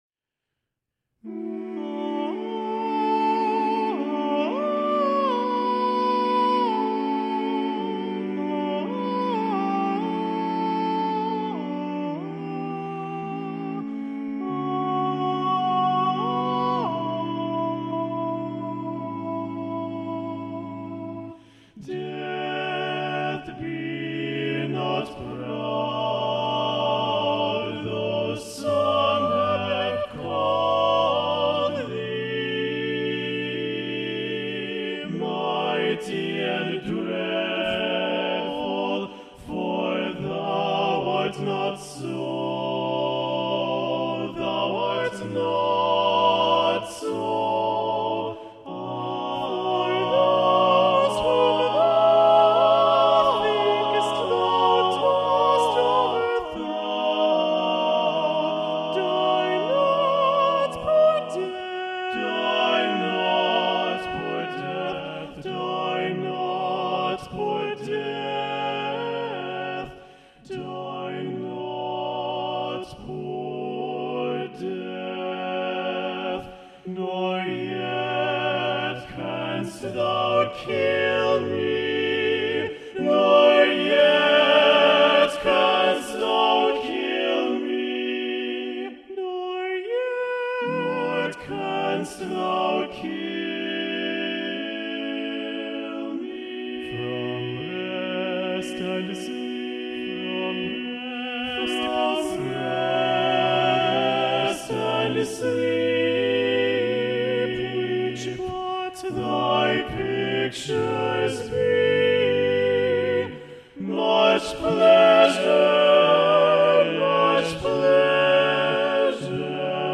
Choral ~ General ~ A Cappella
A serene setting
with a triumphant ending